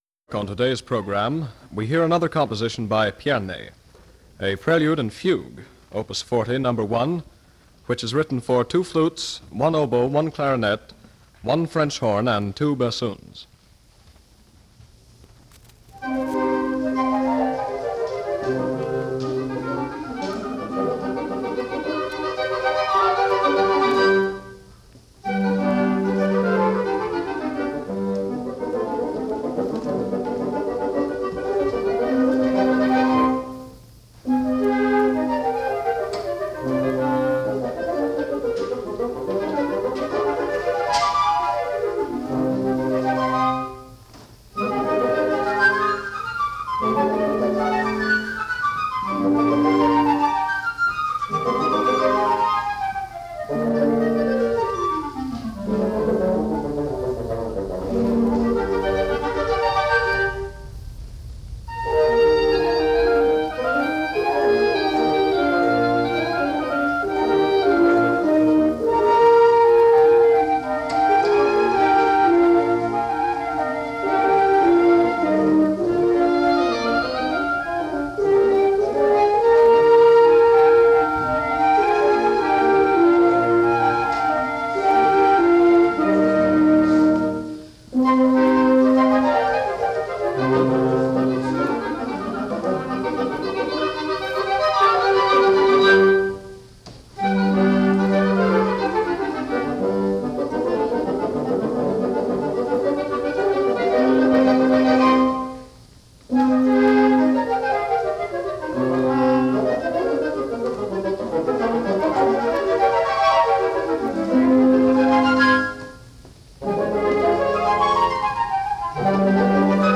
The following pieces were performed at the Curtis Institute of Music by various wind ensembles from 1936 to 1941 under the direction of Marcel Tabuteau.
Format: 78 RPMs